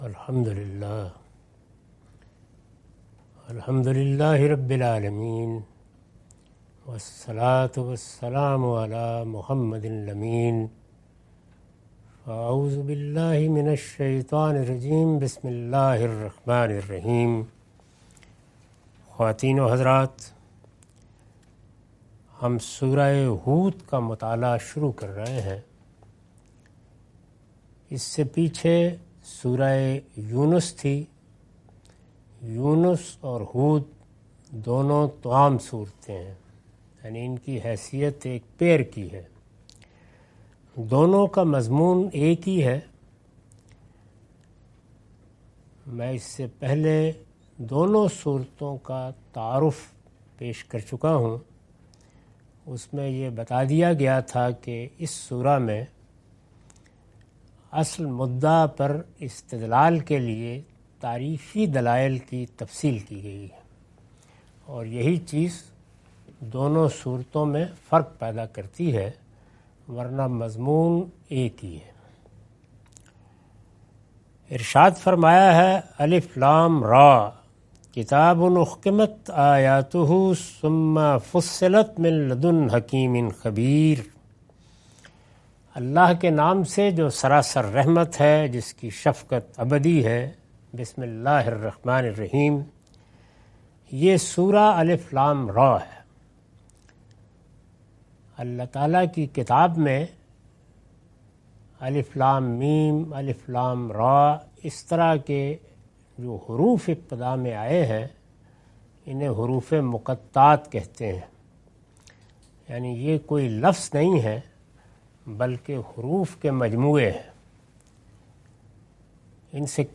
Surah Hud- A lecture of Tafseer-ul-Quran – Al-Bayan by Javed Ahmad Ghamidi. Commentary and explanation of verses 01-07.